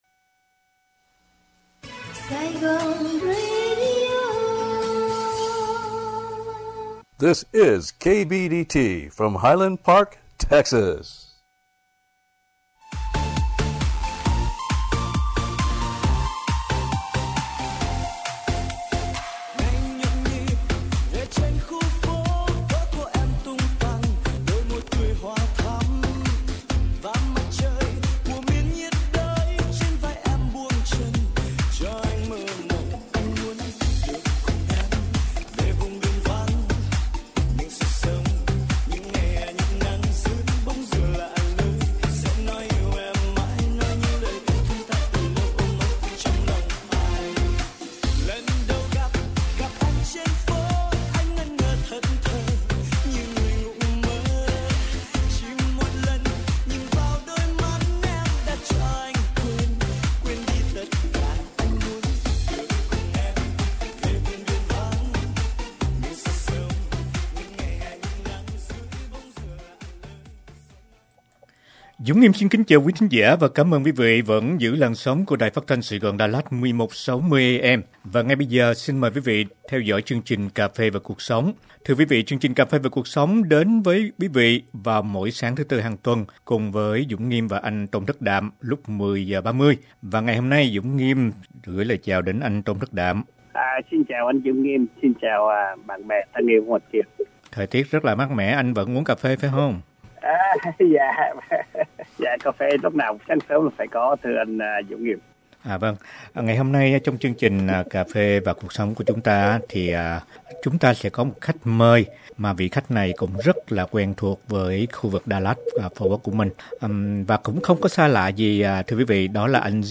Văn Bút Nam Hoa Kỳ: Talk show cùng Nhà văn